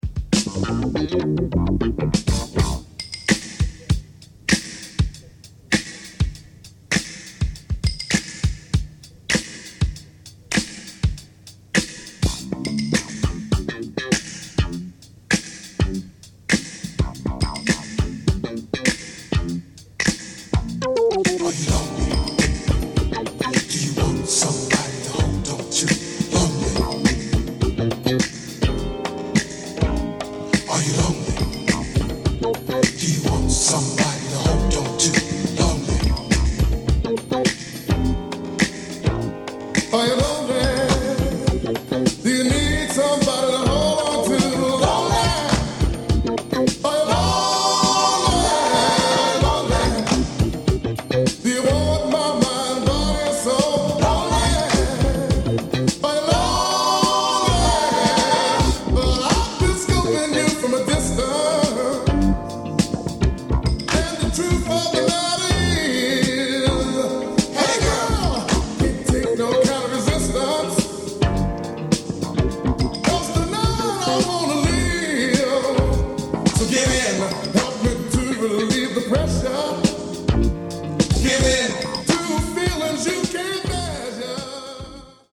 Boogie anthem alert!
Boogie. Disco. Electro